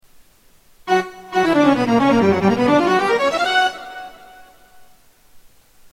Category: Sound FX   Right: Personal
Tags: Sound Effects Genesys Pro Sounds Genesys Pro Genesys Synth Sounds